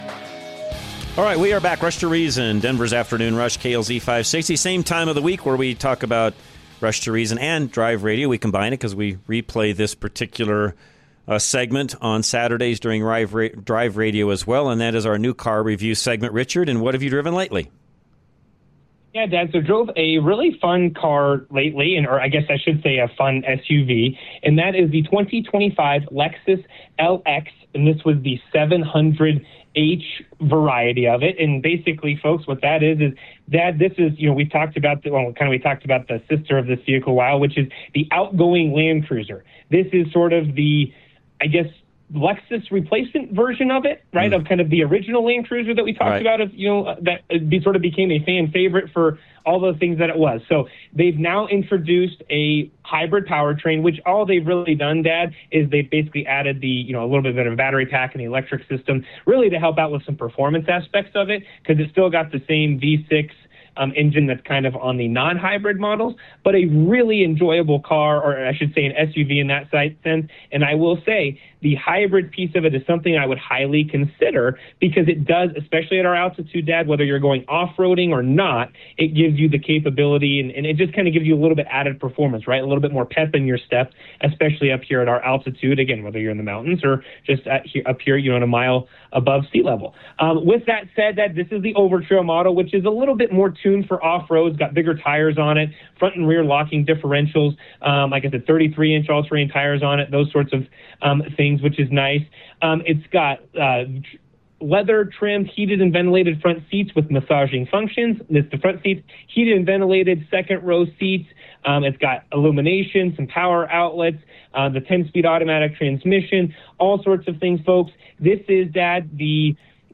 2025 Lexus LX700h Overtrail Review